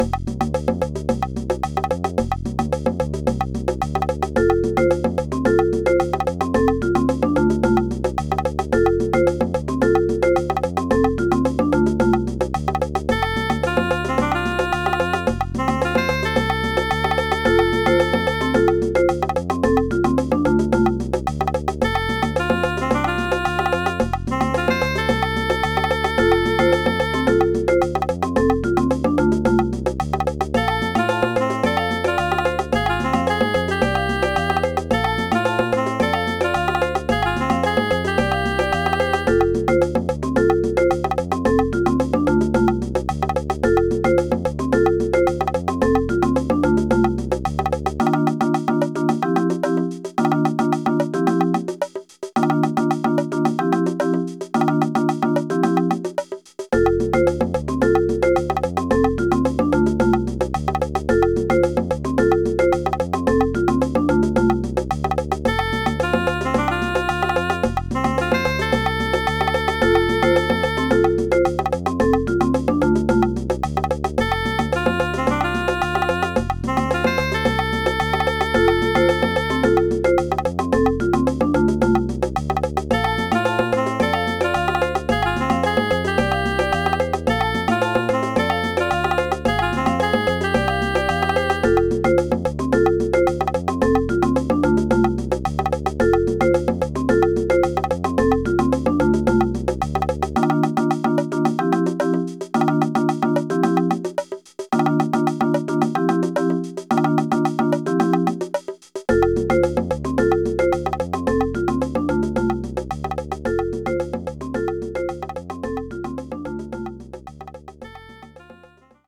ported to SNES